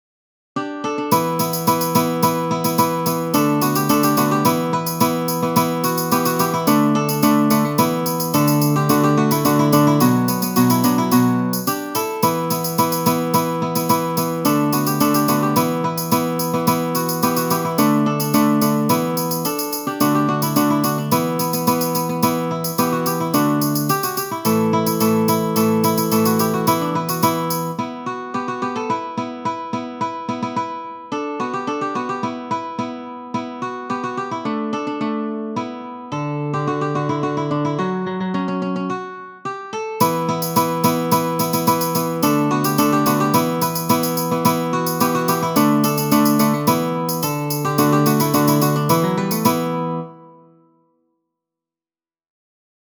medieval-dance-saltarello.wav